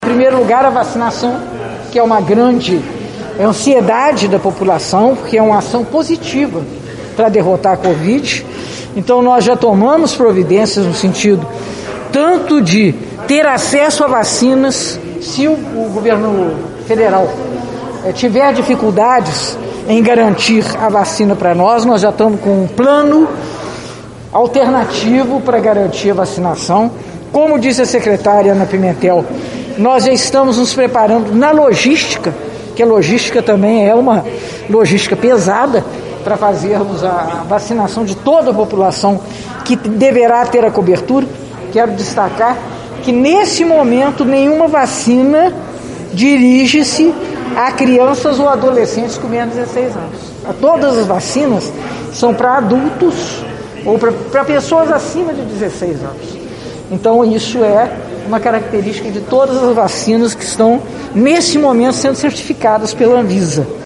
Em coletiva realizada nesta quarta-feira, 30, a prefeita eleita de Juiz de Fora, Margarida Salomão (PT) falou sobre os primeiros atos da nova administração.